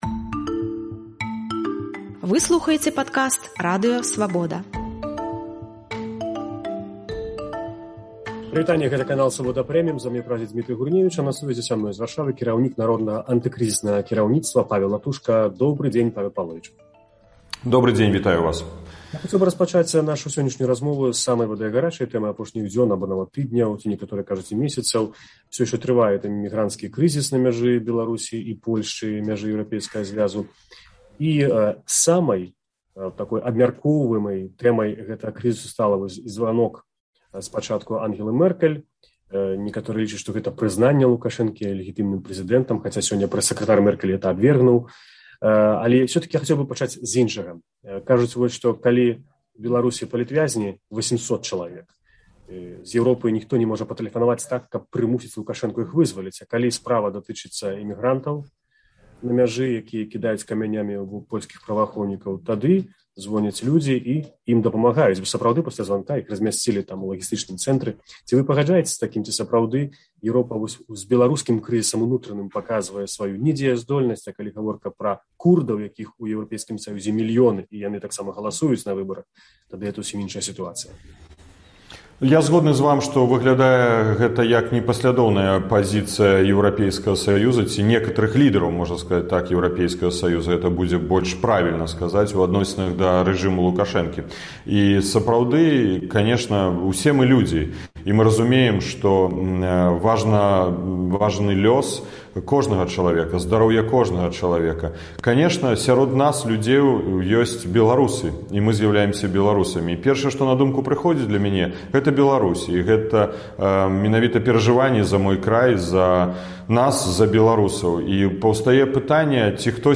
Лідэр Народнага антыкрызіснага кіраўніцтва Павал Латушка ў інтэрвію “Свабодзе” камэнтуе званок Ангелы Мэркель Лукашэнку, разважае пра магчымыя шляхі выхаду зь міграцыйнага крызісу, ацэньвае новыя санкцыі супраць рэжыму ў Менску і раскрывае тактыку і стратэгію дэмакратычных сілаў падчас канстытуцыйнага рэфэрэндуму ў лютым наступнага году.